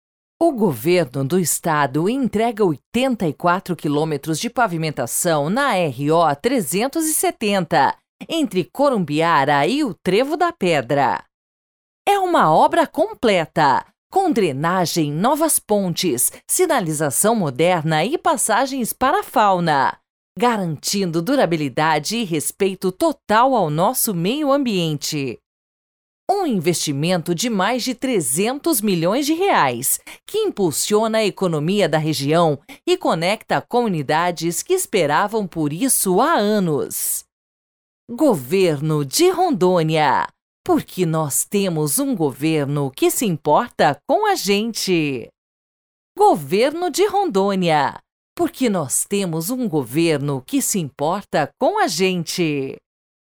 V3 (Produtor Rural - Voz firme e grata): O escoamento da nossa produção vai ser muito melhor agora.
ATENÇÃO: A Narração precisa soar natural, a ideia é que soe autêntico, com uma dicção clara, mas sem o polimento de um locutor de estúdio, focando na praticidade do benefício (economia de tempo e dinheiro).
SFX: Som de carro passando em asfalto liso (zumbido suave). Som de vento na estrada.
TRILHA: Instrumental vibrante, com ritmo de progresso e batida constante.